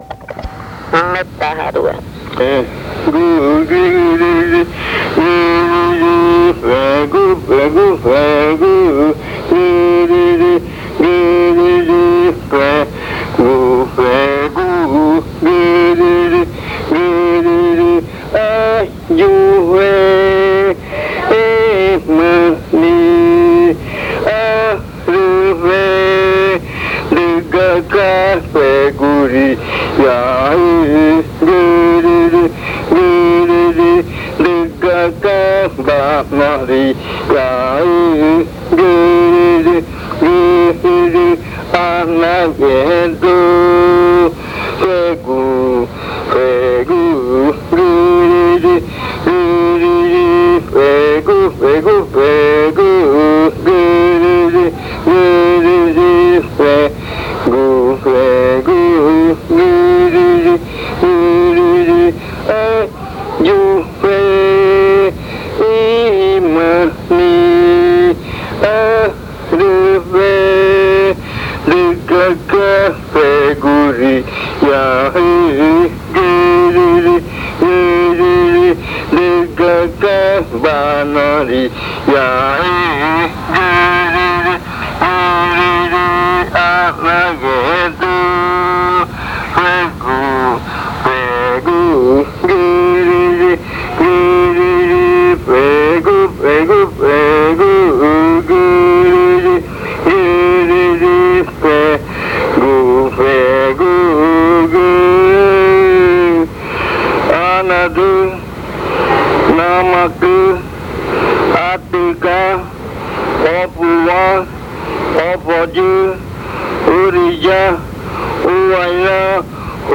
Continuación de la arrimada del baile (netaja rua). Sonido que recuerda la caída de frutas.
Continuation of the entry chant (netaja rua). Sound reminiscent of falling fruits.